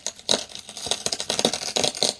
freezer_creak1.ogg